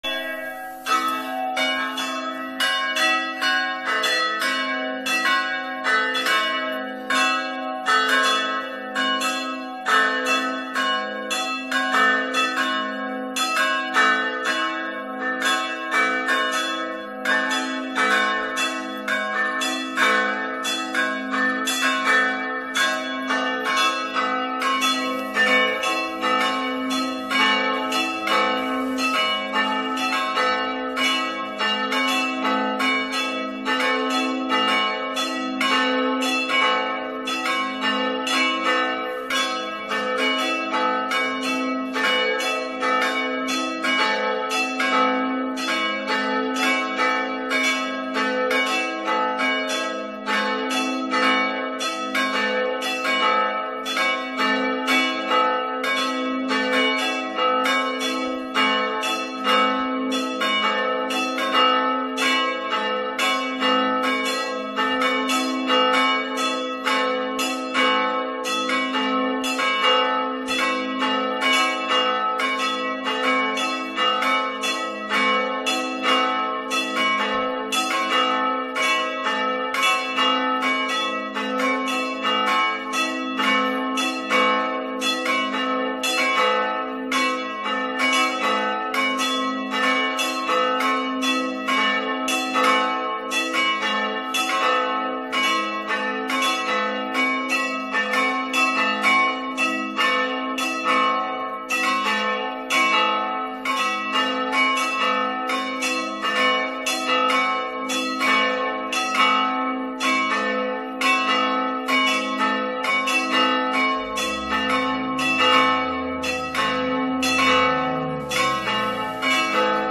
Posłuchaj dzwonów kościelnych Parafii Pniów
dzwony_pniow.mp3